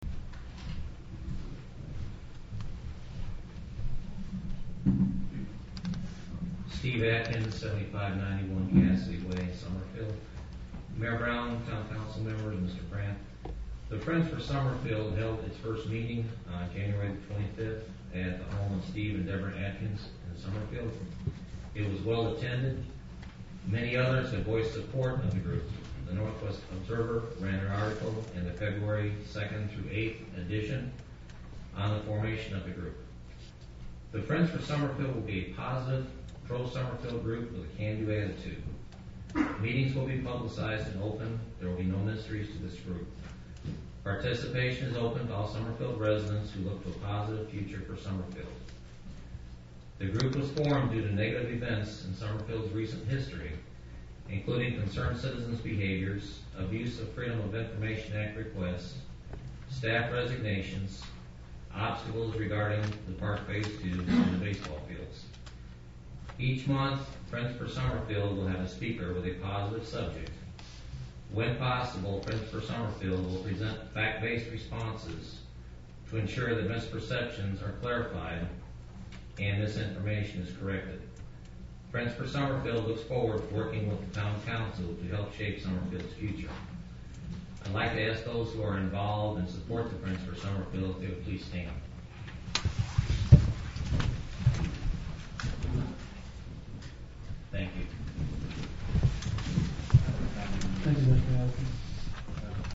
2-13-07 Sound recordings from town council meeting
Although not part of the official town recording, they have not been intentionally altered and are presented as is.